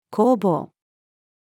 攻防-female.mp3